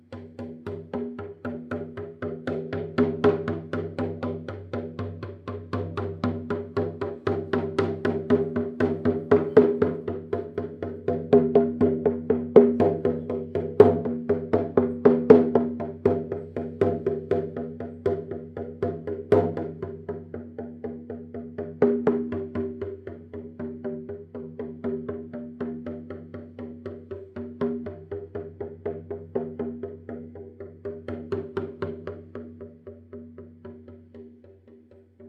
Šamanský buben
Bubnování zní jako tlukot srdce Matky Země a navrací vše do rovnováhy.
samansky-buben177.mp3